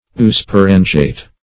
eusporangiate - definition of eusporangiate - synonyms, pronunciation, spelling from Free Dictionary
eusporangiate.mp3